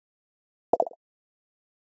Звуки MacBook Pro и iMac скачать mp3 - Zvukitop